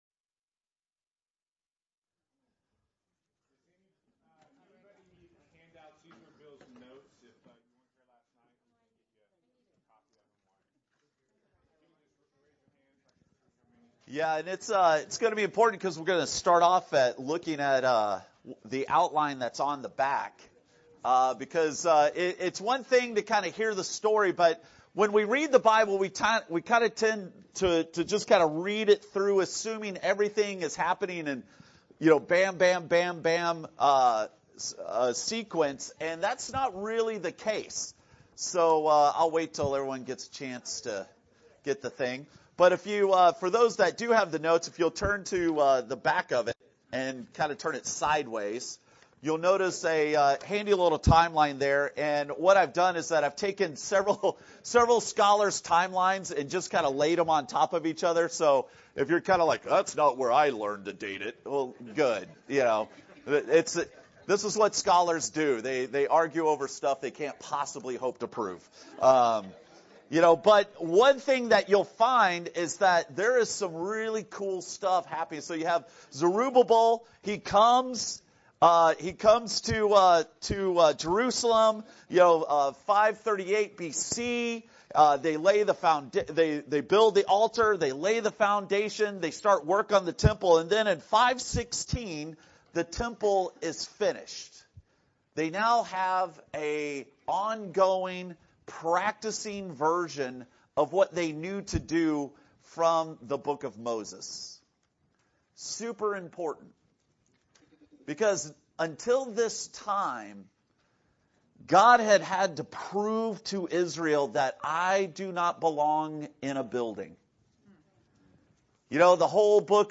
Guest Speaker
Sermon